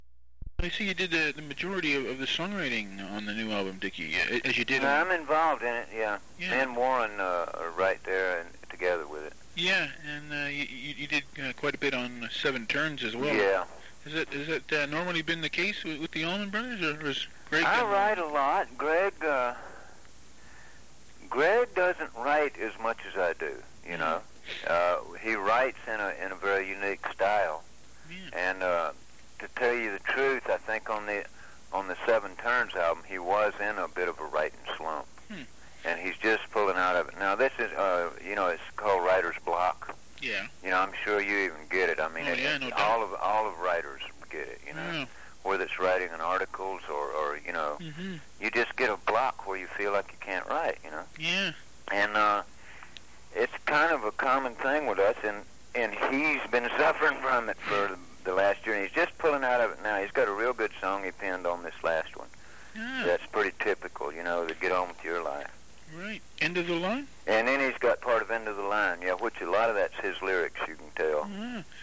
When I interviewed Allman Brothers guitarist Dickey Betts back in July of 1991, the band had just released its Shades of Two Worlds album, its second to feature the amazing Warren Haynes as a fully-fledged member.